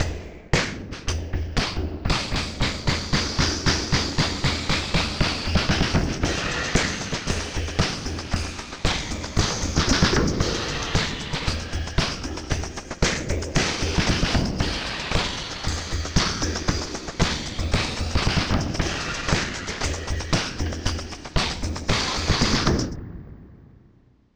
DR Mash Upside Down on Drums